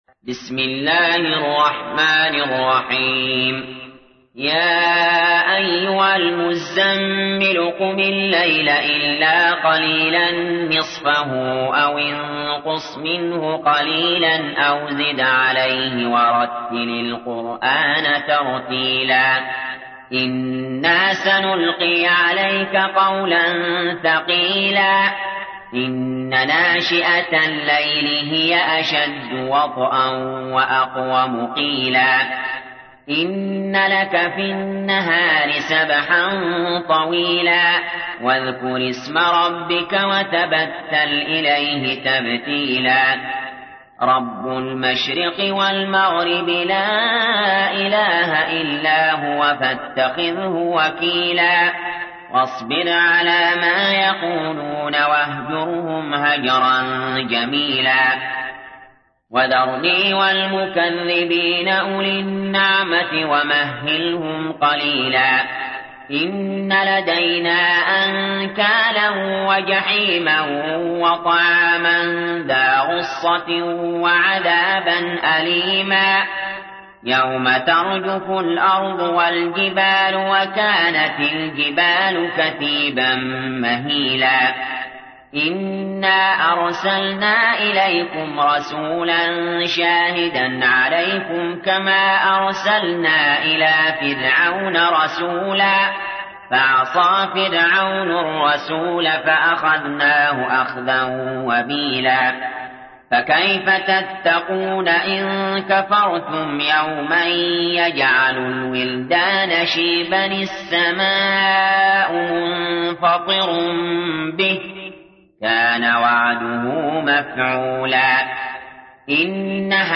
تحميل : 73. سورة المزمل / القارئ علي جابر / القرآن الكريم / موقع يا حسين